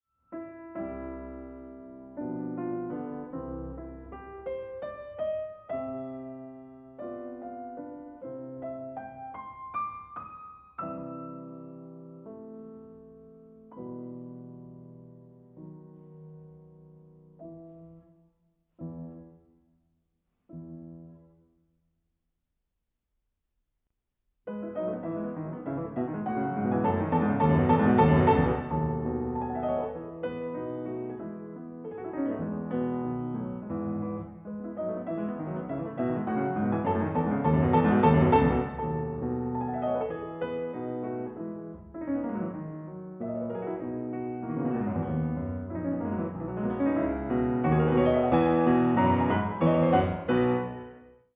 Perhaps strange in isolation, this interpretation works wonders for the structure as a whole, with the slightly 'loose' and melancholic middle movement sandwiched between two boisterous outer movements.
Note the brilliant hesitation that heralds in a softer dynamic.
PIANO MUSIC